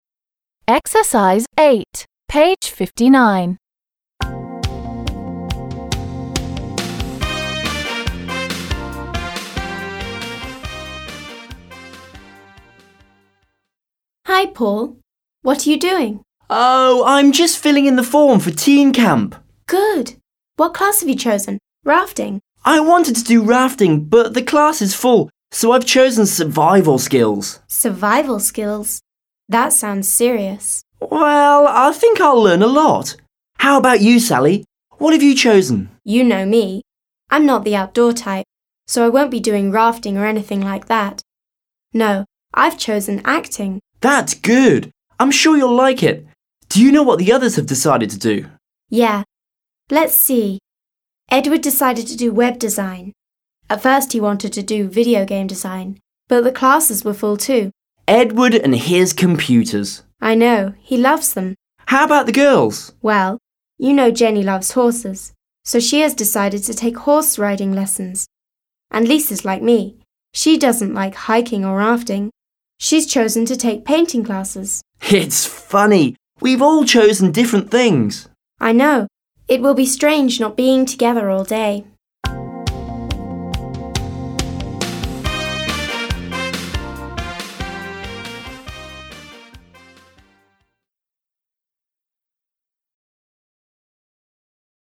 8. Listen to Paul and Sally talking about the classes at Teen Camp. What class has each person chosen? − Послушайте, как Пол и Салли обсуждают занятия в подростковом лагере. Какой предмет выбрал каждый из них?